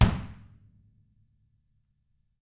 Index of /90_sSampleCDs/ILIO - Double Platinum Drums 1/CD2/Partition A/REMO KICK R